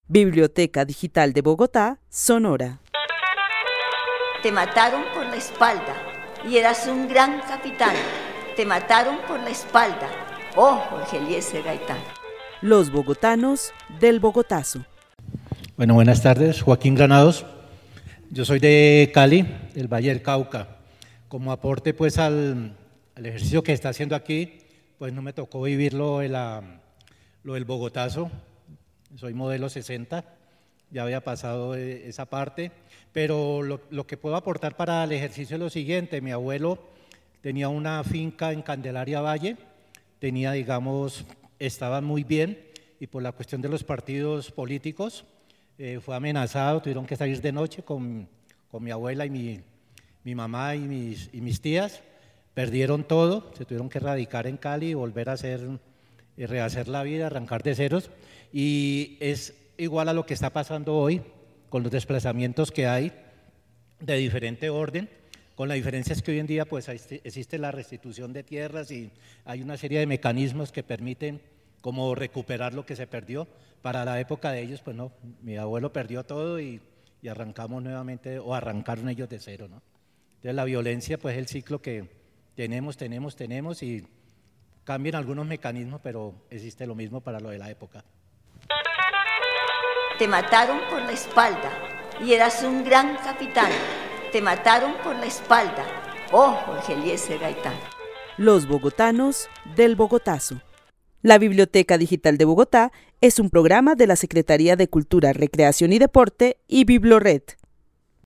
Narración oral sobre las consecuencias que tuvo la violencia bipartidista en su familia entre los años 50 y 60. El testimonio fue grabado en el marco de la actividad "Los bogotanos del Bogotazo" con el club de adultos mayores de la Biblioteca Carlos E. Restrepo.